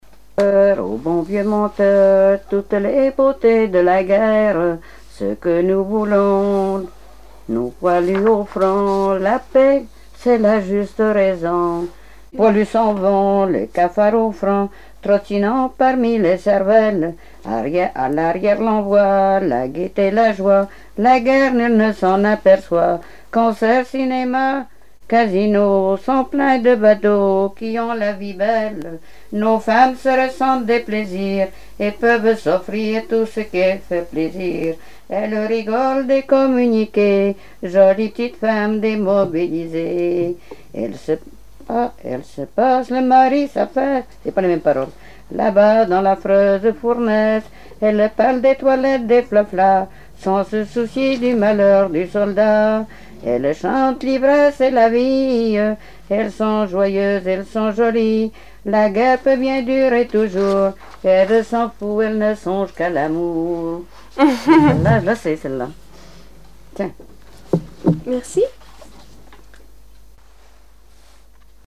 Genre strophique
Répertoire de chansons traditionnelles et populaires
Pièce musicale inédite